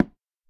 latest / assets / minecraft / sounds / dig / wood2.ogg
wood2.ogg